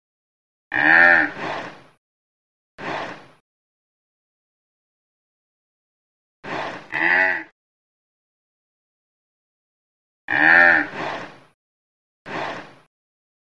B_TAUREAU.mp3